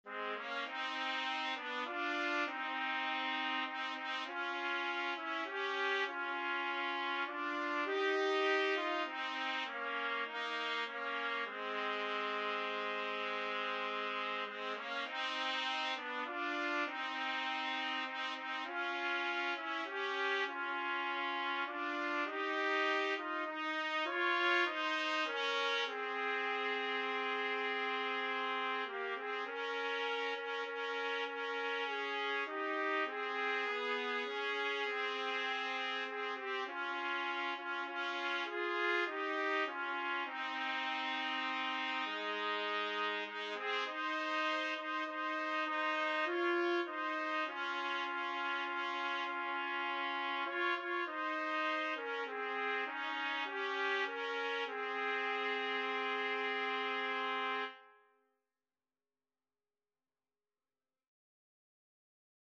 Trumpet 1Trumpet 2
6/8 (View more 6/8 Music)
Classical (View more Classical Trumpet Duet Music)